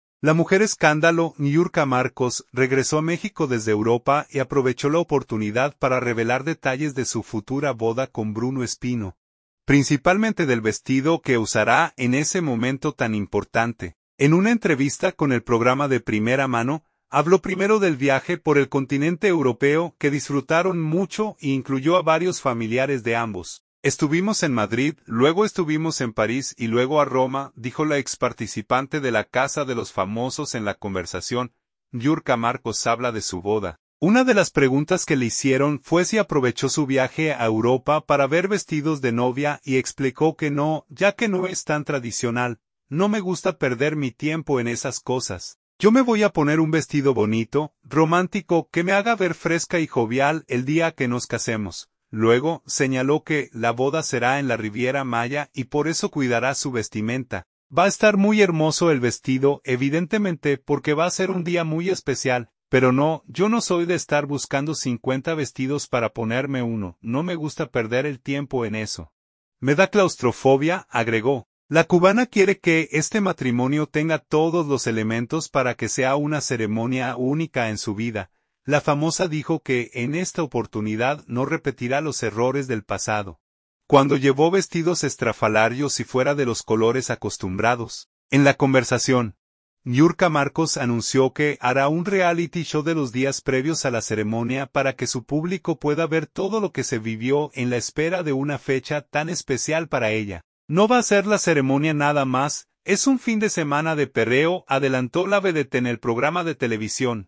En una entrevista con el programa De Primera Mano, habló primero del viaje por el continente europeo, que disfrutaron mucho e incluyó a varios familiares de ambos.